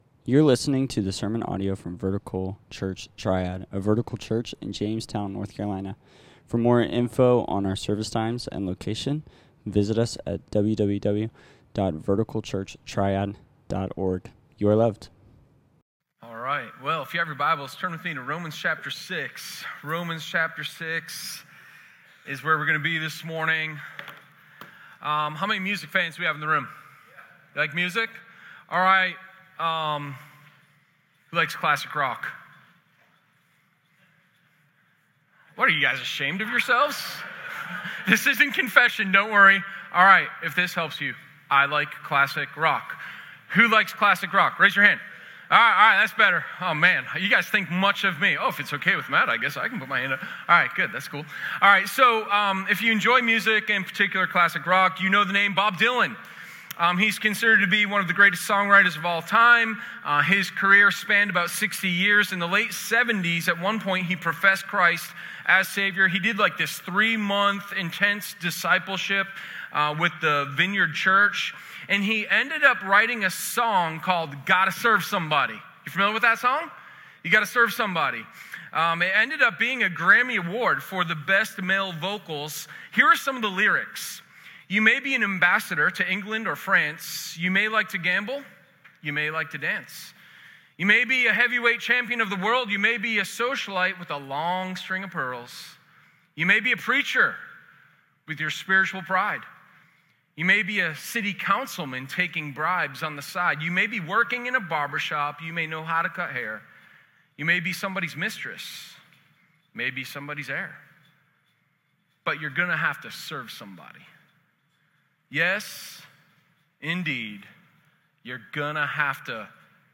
Sermon0507_YouGottaServeSomebody.m4a